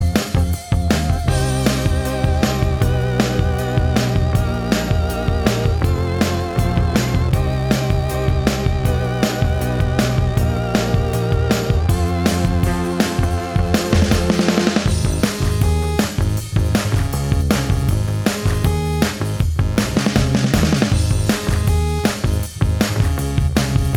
No Guitars Pop (2000s) 3:50 Buy £1.50